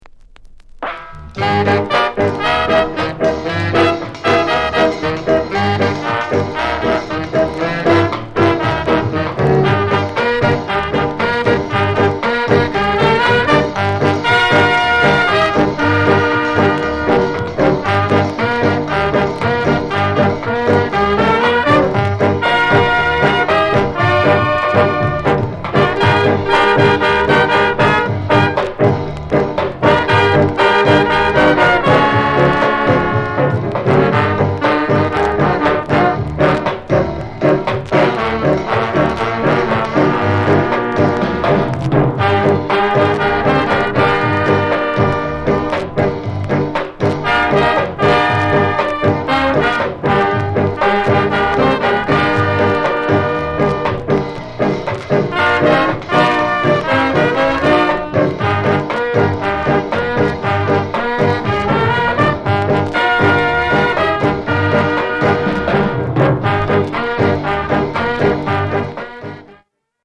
KILLER SKA INST